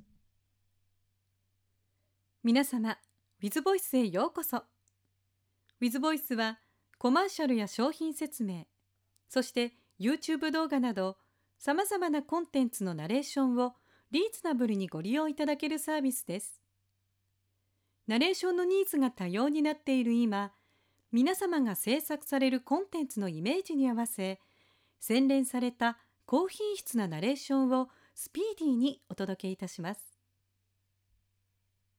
ボイスサンプル
プロのナレーターが幅広いジャンルに対応し、お客様のご要望にお応えします。